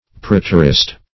Preterist \Pret"er*ist\, n. [Pref. preter- + -ist.]